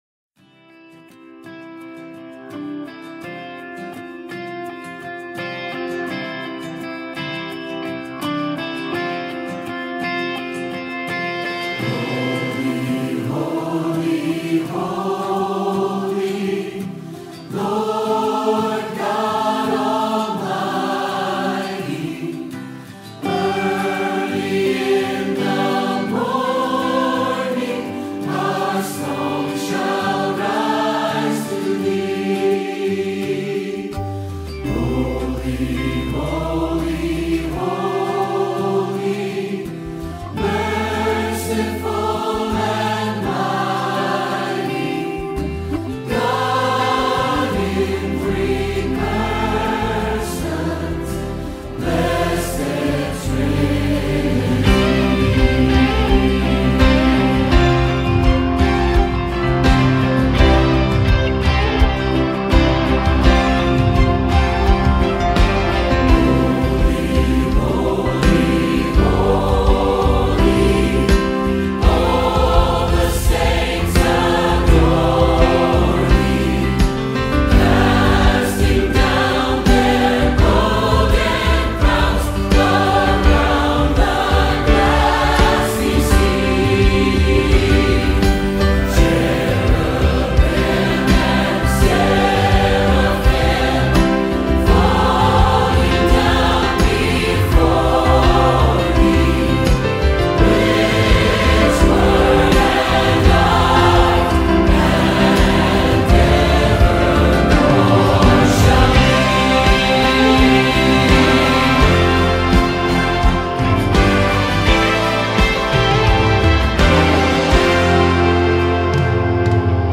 Our service begins with the singing one of two hymns, the more contemporary song being found here and the traditional hymn being here.
Welcome to this time of worship.